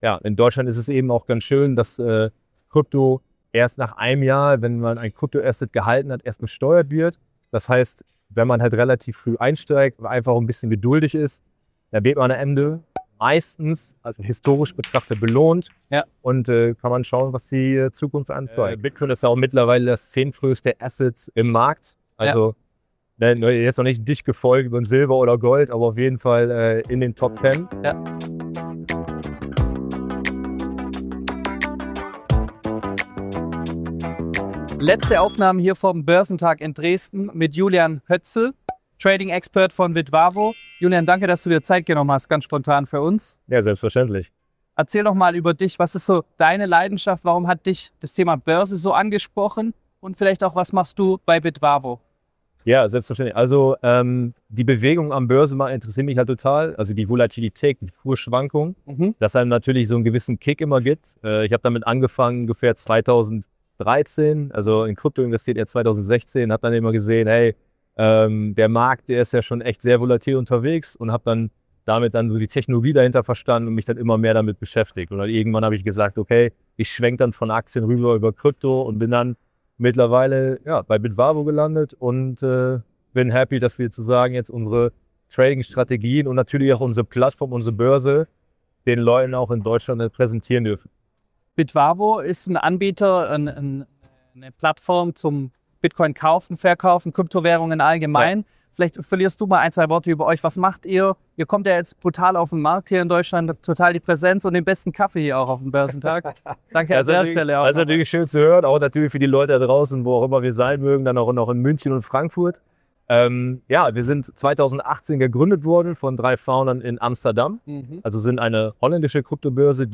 Vom Börsentag Dresden